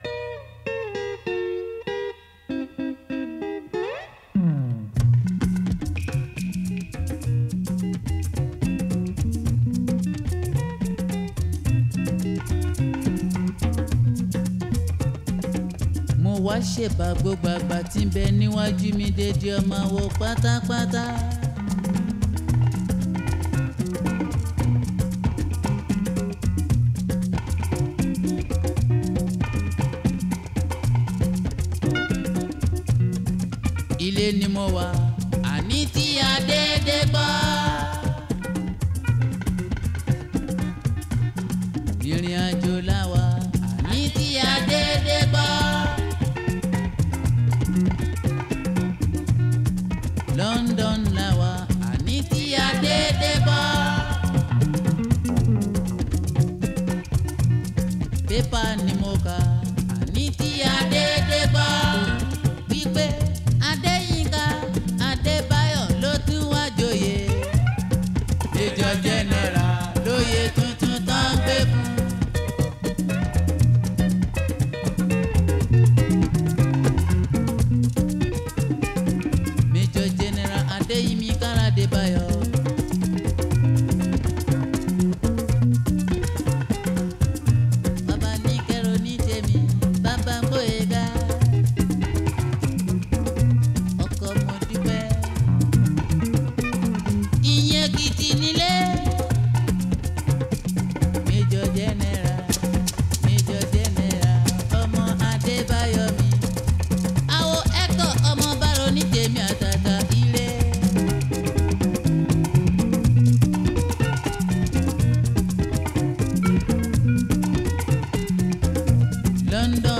is a Nigerian jùjú singer